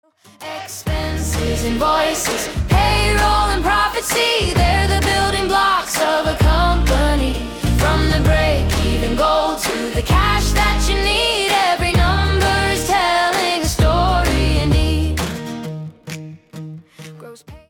Features two incredibly catchy tracks